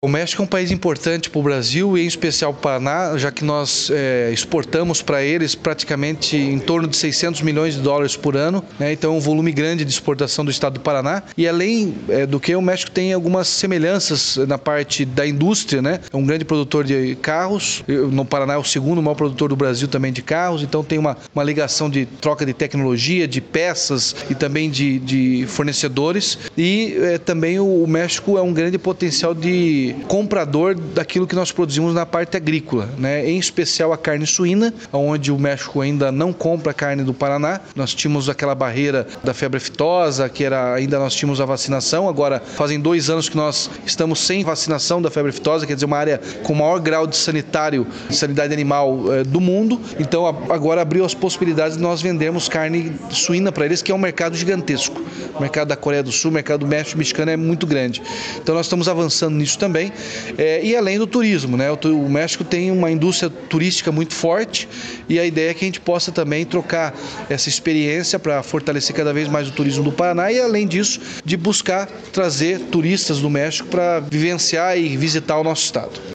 Sonora do governador Ratinho Junior sobre a possibilidade de estreitamento das relações entre o Paraná e o México | Governo do Estado do Paraná
RATINHO JUNIOR - ENCONTRO EMBAIXADORA MEXICO.mp3